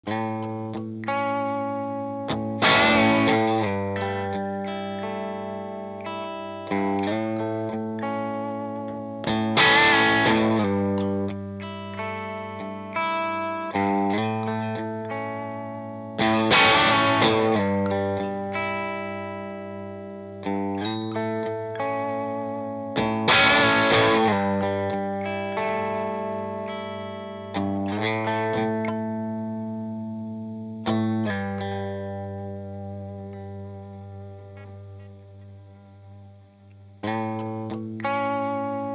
Originale Rickenbacker-BossRC20-Matamp-V30-Heil-Fat2.wav